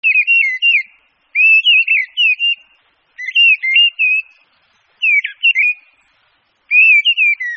Le Tarier pâtre